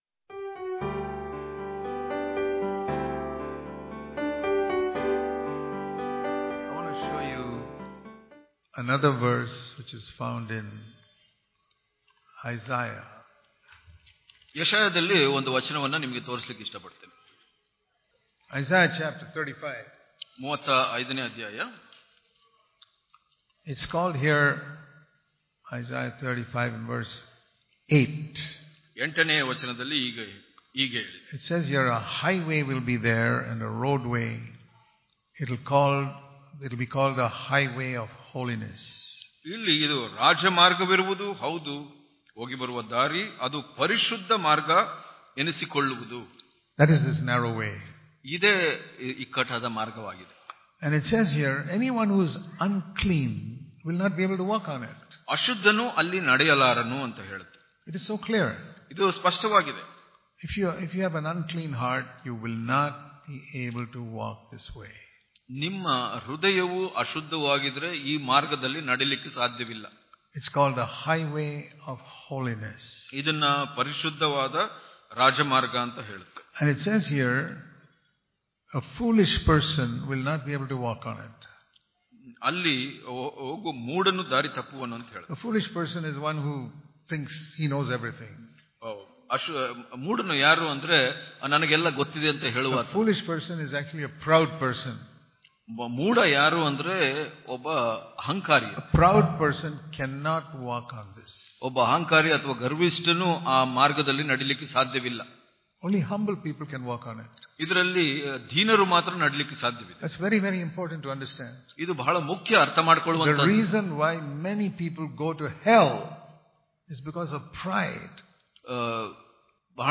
March 26 | Kannada Daily Devotion | Humility Is The Way To Draw Closer To God Daily Devotions